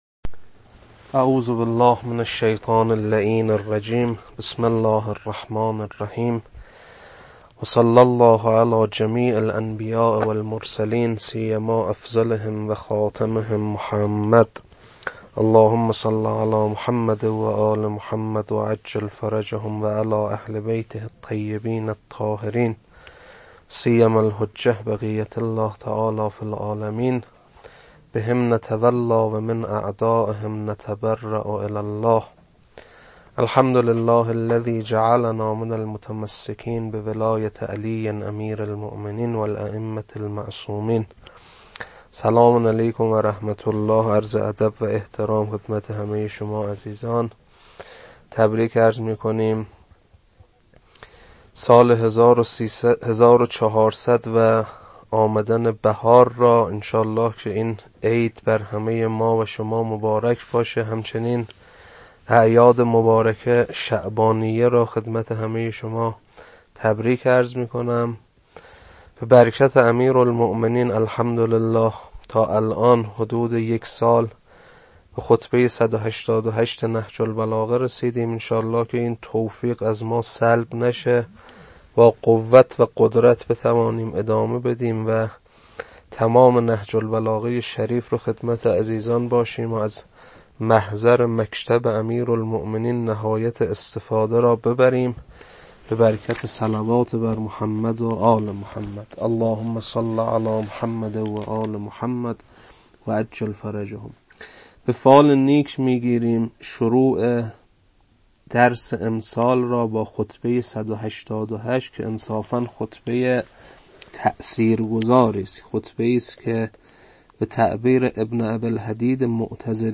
خطبه 188.mp3